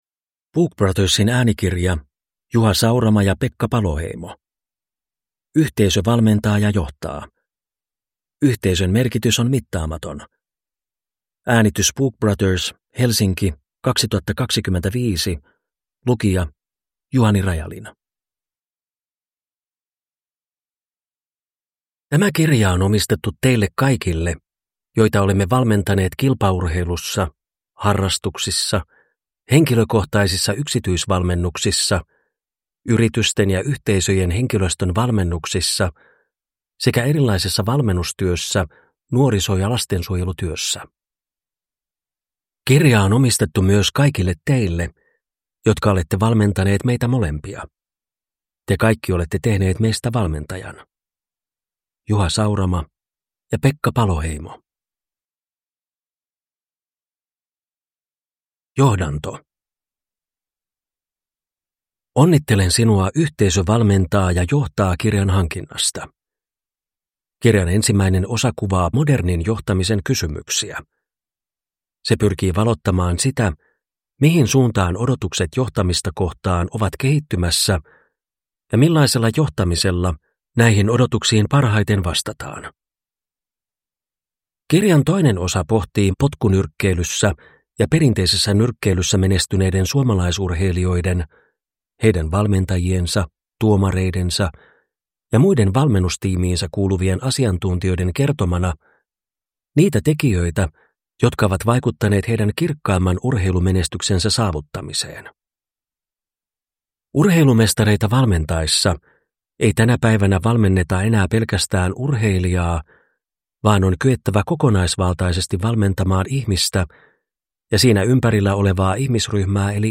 Yhteisö valmentaa ja johtaa – Ljudbok